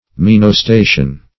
Menostation \Men`os*ta"tion\, n.